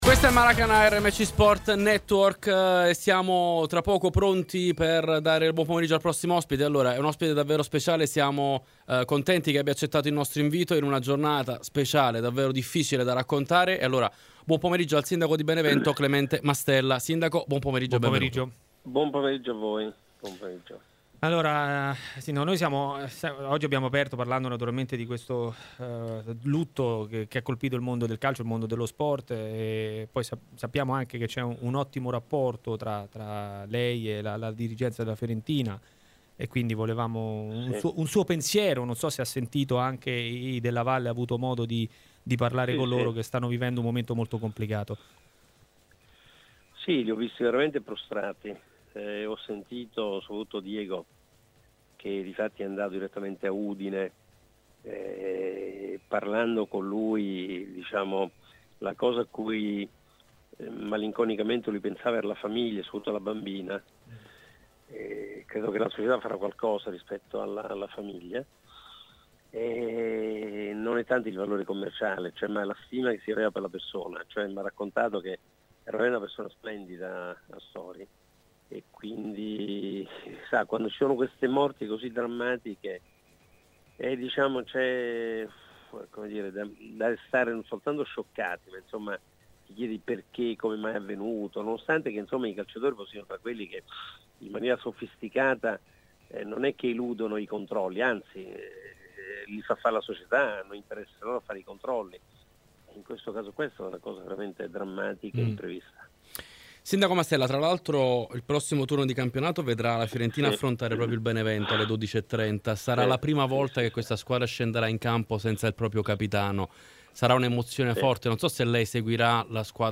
In diretta su RMCSport c'è Clemente Mastella, sindaco di Benevento e molto amico della famiglia Della Valle, proprietaria della Fiorentina, scossa ieri dalla scomparsa di Davide Astori:
intervistato